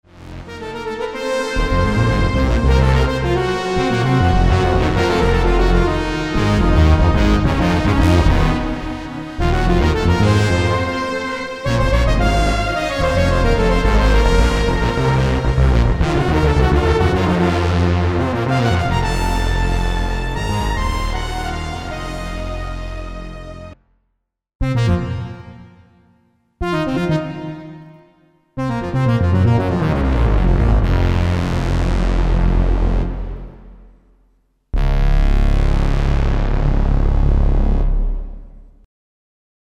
dce_combi_vangelis_like.mp3